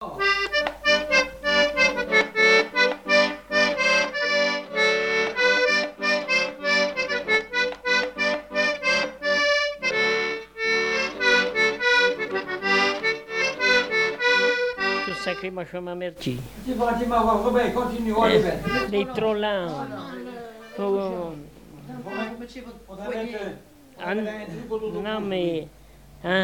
Couplets à danser
branle : courante, maraîchine
Pièce musicale inédite